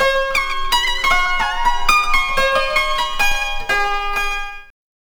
CHINAZITH4-R.wav